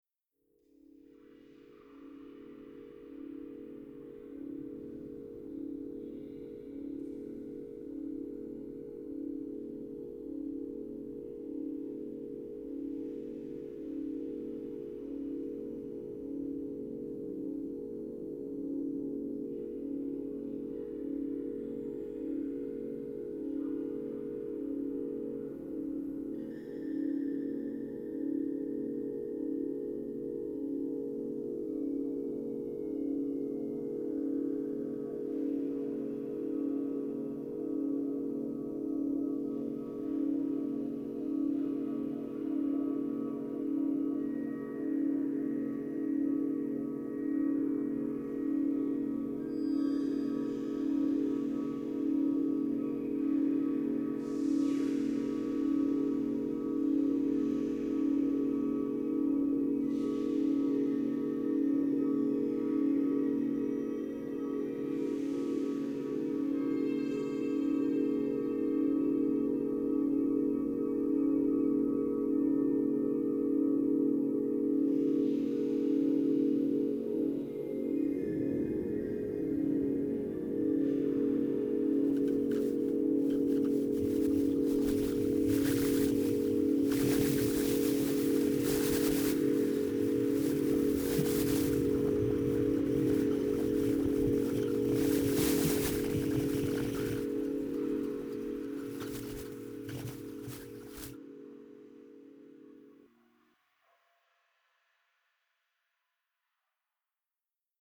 SlowSynthWalk.mp3